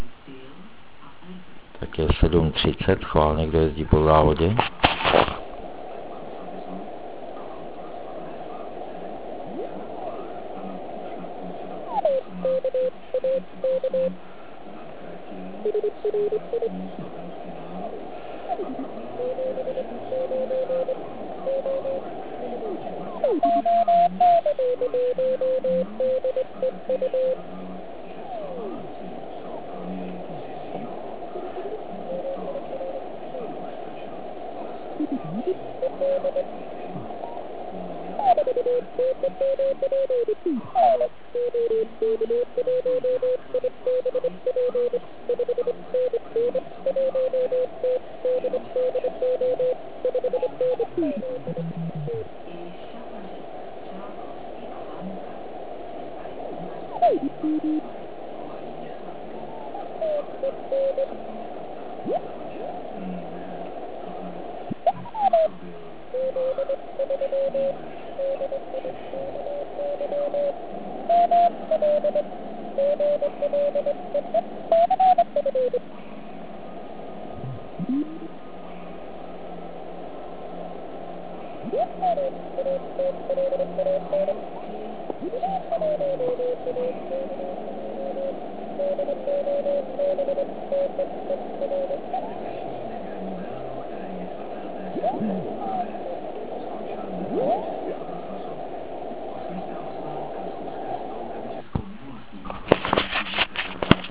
Stanice bez hodin DCF (*.wav záznam po skončení závodu)